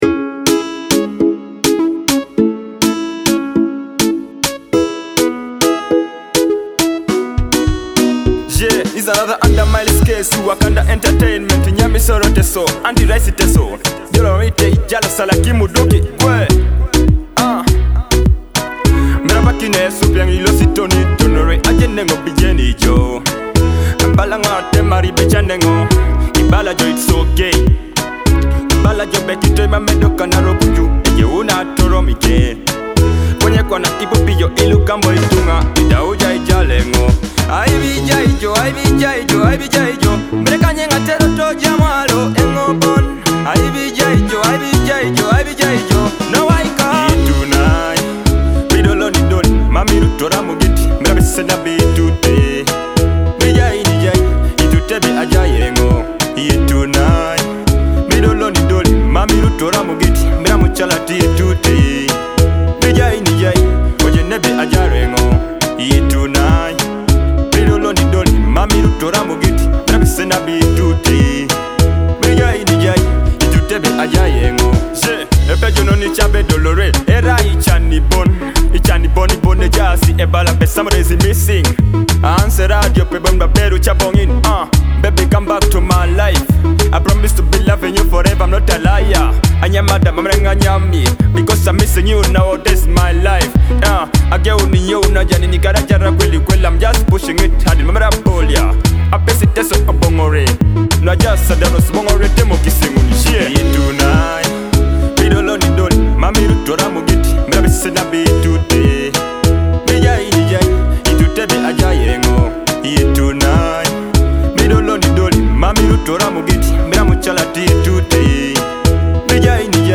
dance hall track
brings vibrant energy and infectious beats